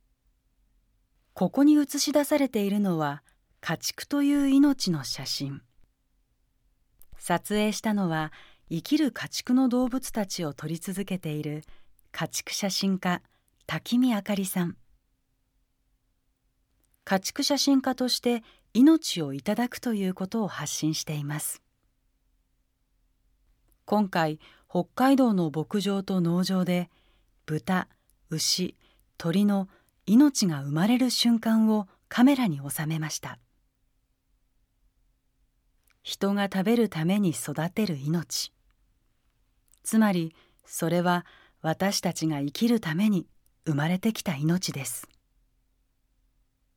飛ぶ鳥農場 音声ガイドナレーター：宇賀なつみ